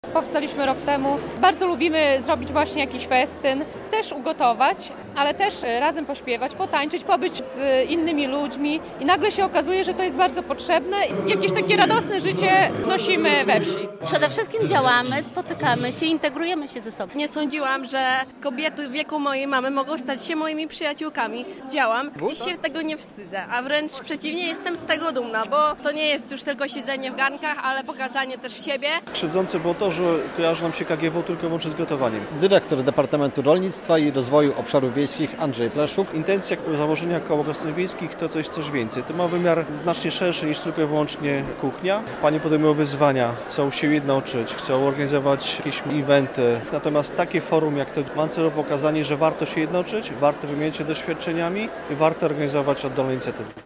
II Samorządowe Forum Kół Gospodyń Wiejskich - relacja